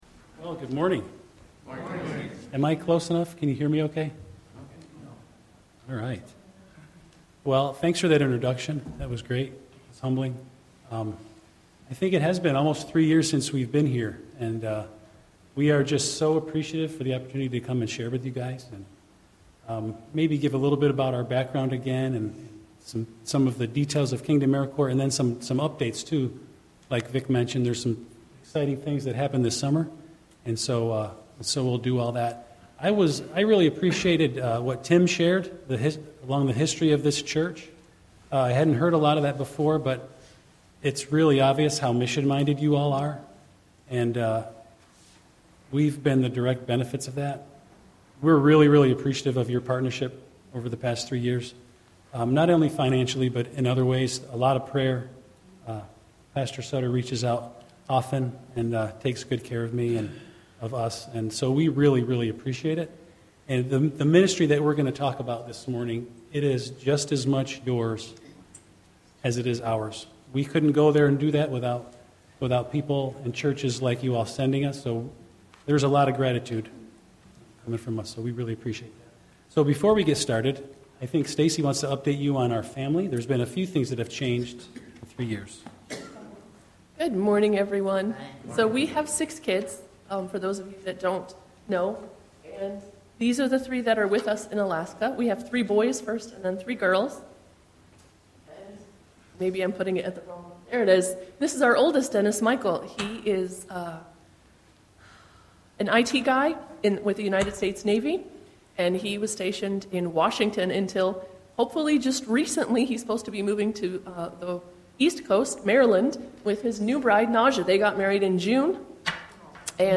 YBC Worship Service – 11/9/2025
Watch Online Service recorded at 9:45 Sunday morning.